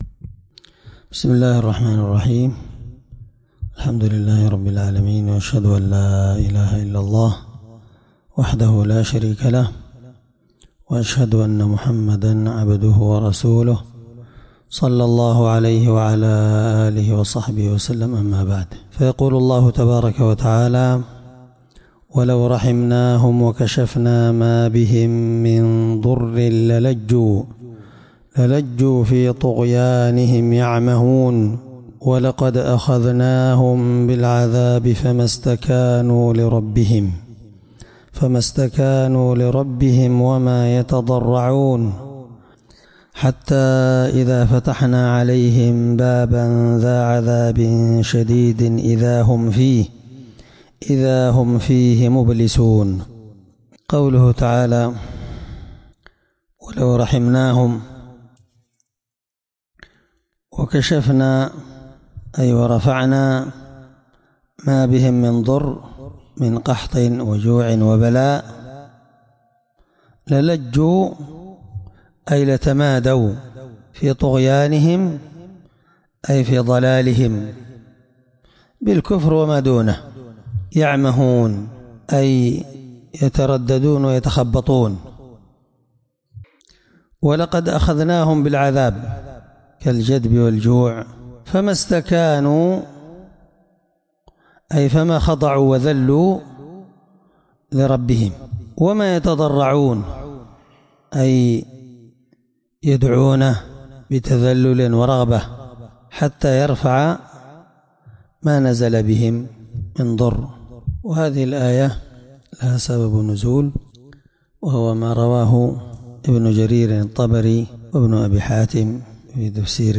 الدرس18تفسير آية (75-77) من سورة المؤمنون